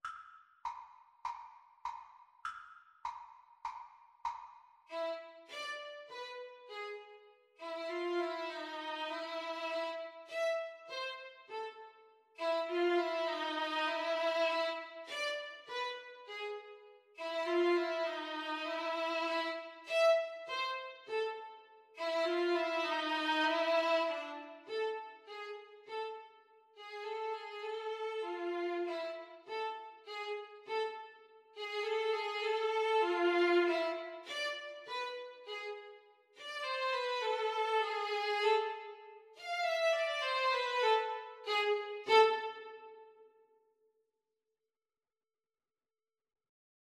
4/4 (View more 4/4 Music)
Tempo di Tango